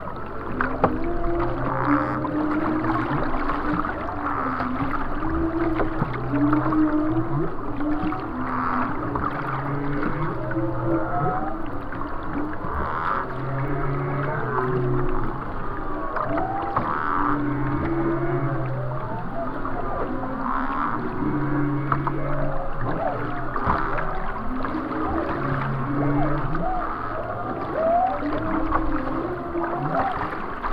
Whales Singing/Improvising Together in Maui. Recording from David Rothenberg.
maui-whale-soup.wav